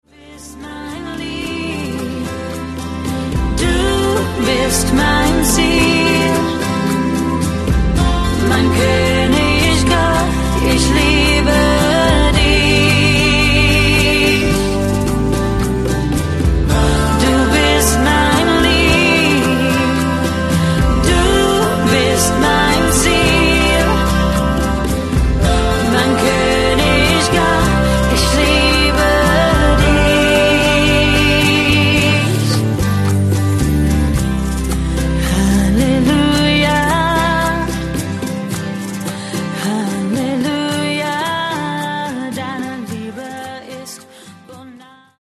Worship-Album